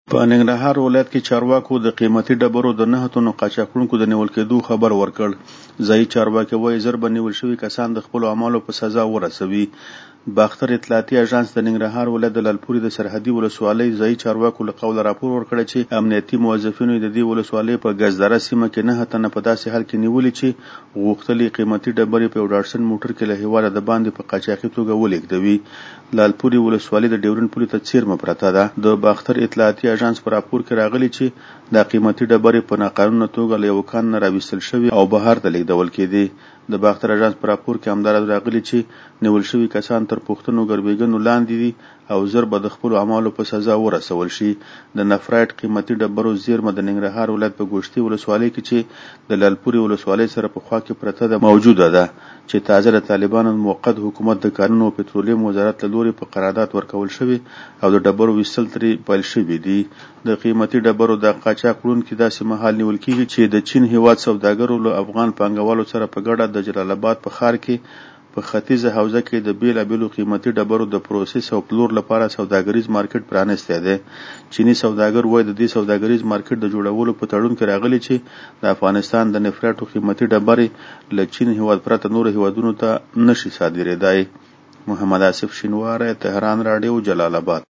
له جلال آباده زمونږ خبریال رپورټ راکړی چې په ننګرهار ولایت کې چارواکو د قیمتي ډبرو د نهه تنو قاچاق وړونکو دنیول کیدو خبر ورکړ.